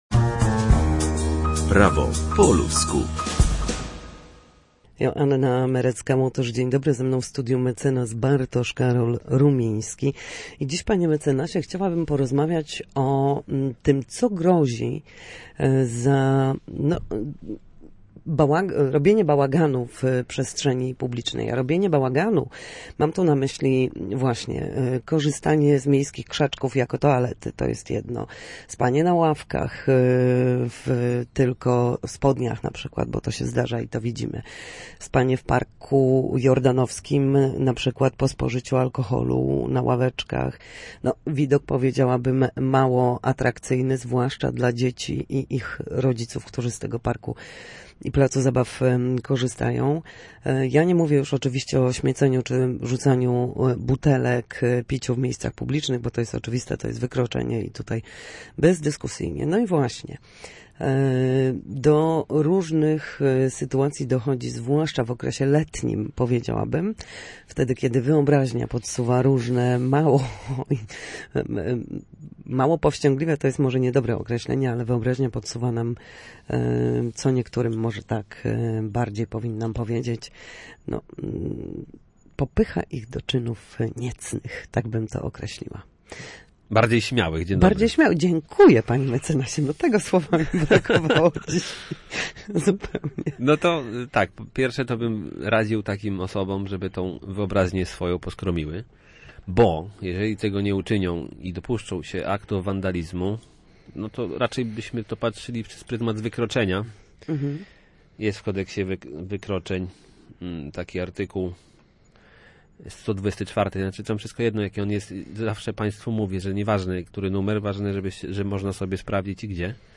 W każdy wtorek o godzinie 13:40 na antenie Studia Słupsk przybliżamy państwu meandry prawa.